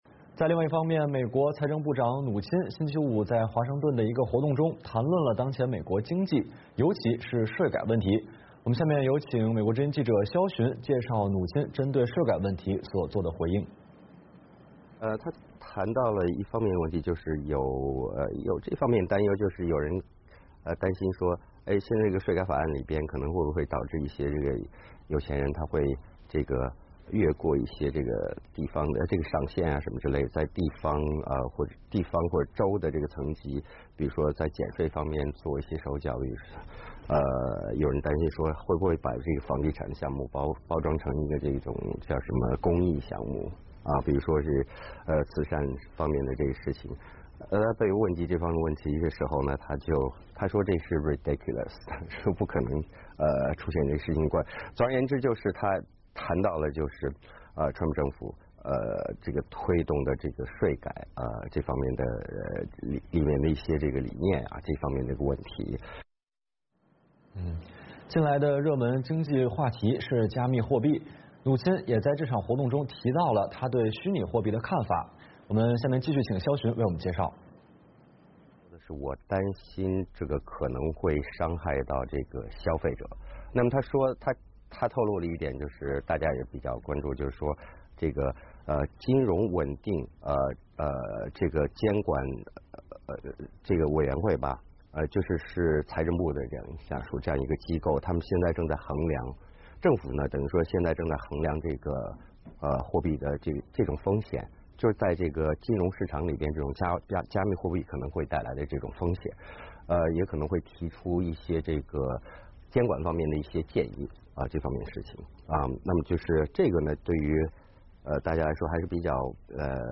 美国财长姆努钦星期五在华盛顿经济俱乐部举行的一个活动中以漫谈形式对当前美国经济中的一些重要问题发表了看法。